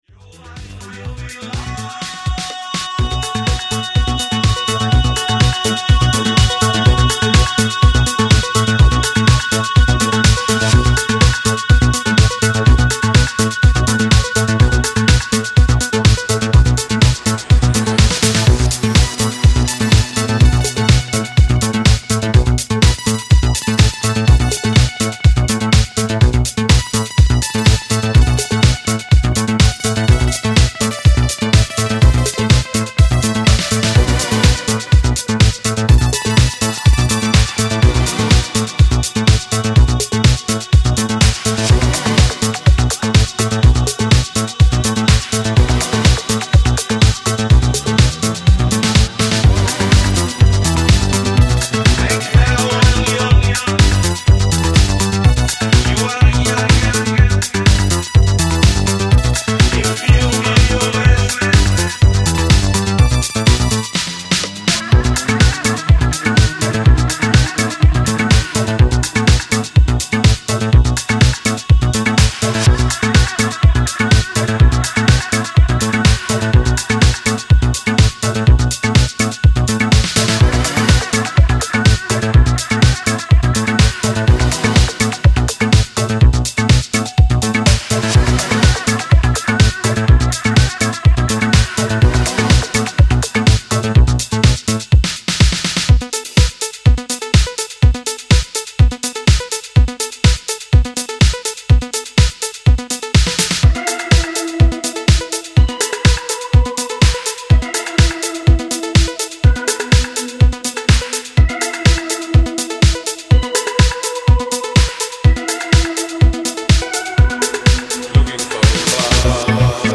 Italo classic rework alert!